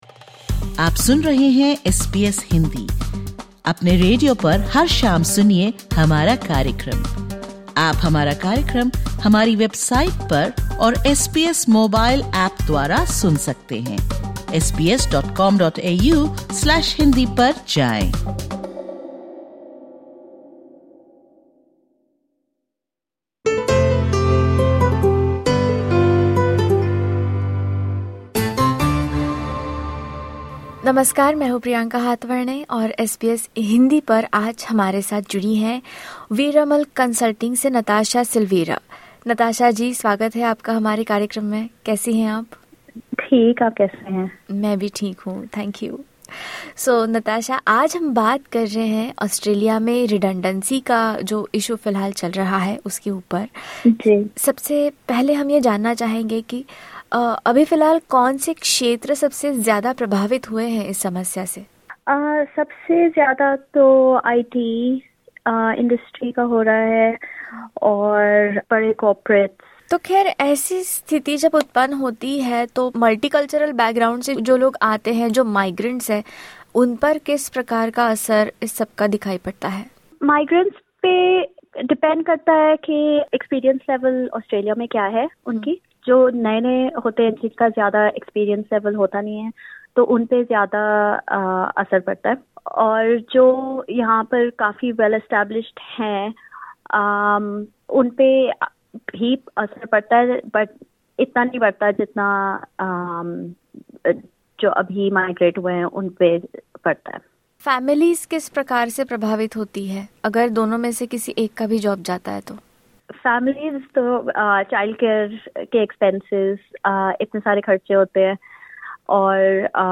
Across Australia, various sectors, including IT, media, and banking, are experiencing redundancies. In this podcast, SBS Hindi interviews